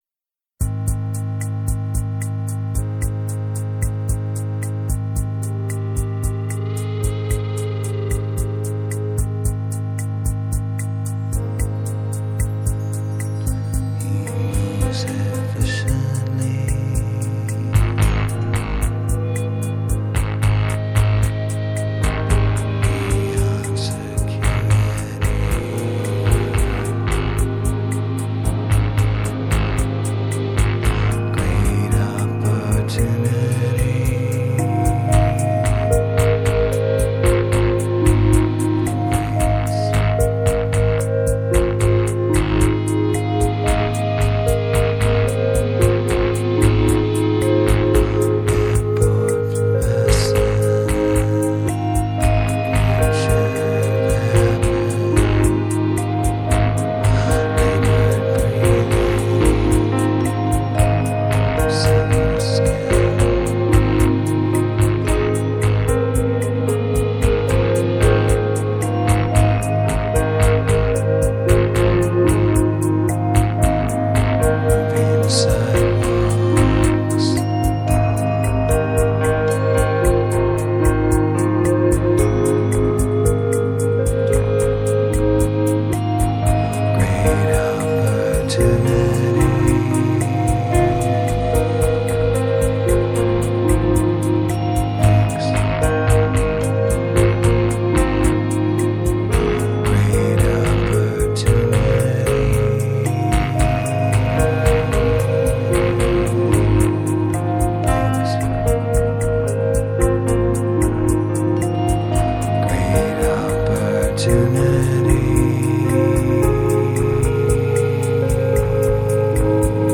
Indie Rock, Pop Rock, Electronic